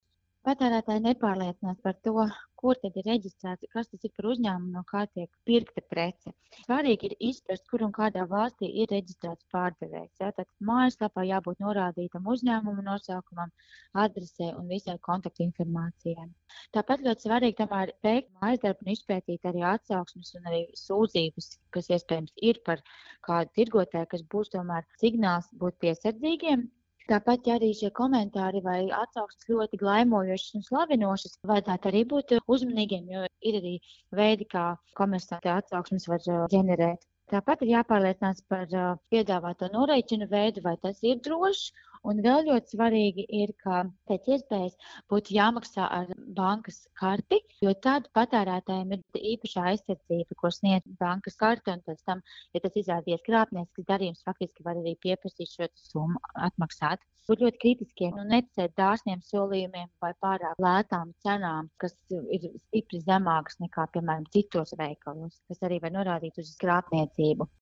RADIO SKONTO Ziņās par drošu iepirkšanos internetā